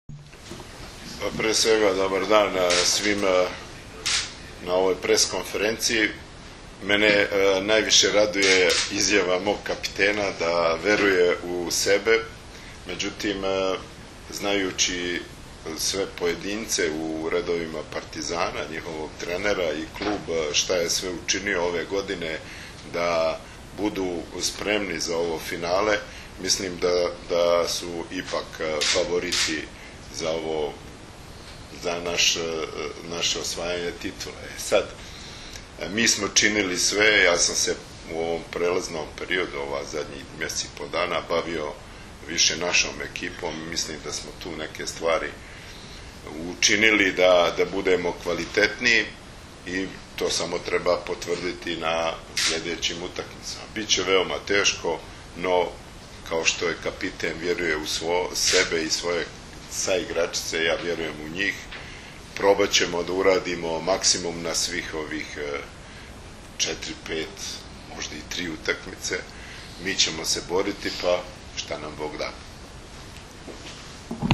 Tim povodom, danas je u prostorijama Odbojkaškog saveza Srbije održana konferencija za novinare